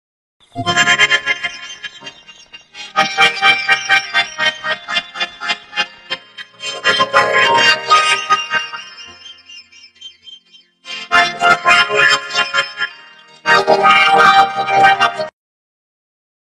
Risada Atumalaca autotune
Categoria: Risadas
Com a risada mais engraçada e o toque inconfundível do autotune, esse áudio vai garantir boas gargalhadas e deixar seus amigos se divertindo.
risada-atumalaca-autotune-pt-www_tiengdong_com.mp3